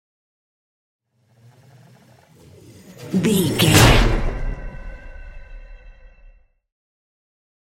Trailer dramatic whoosh to hit 451
Sound Effects
Fast paced
In-crescendo
Atonal
intense
tension
woosh to hit